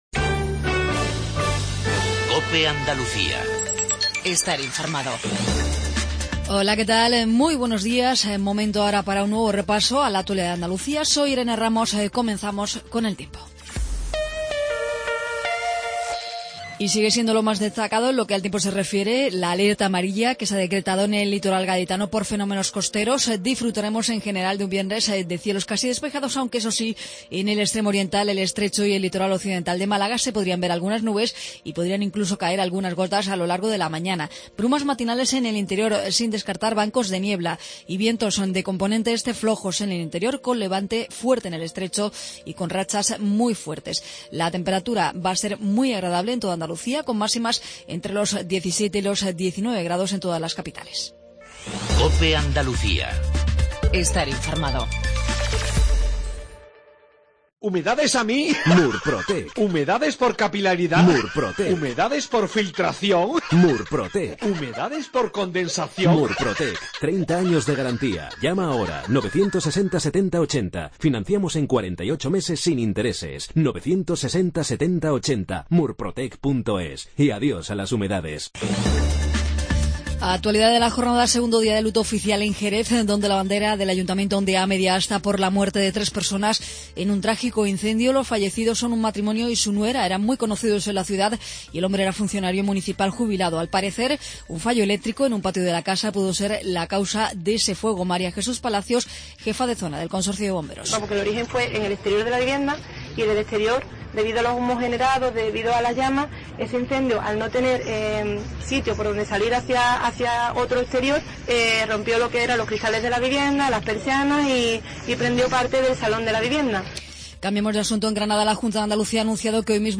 INFORMATIVO REGIONAL/LOCAL MATINAL 8:20